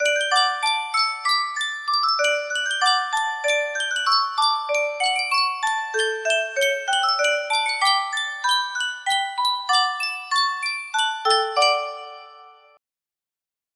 Yunsheng Music Box - While shepherds watched their flocks 2021 music box melody
Full range 60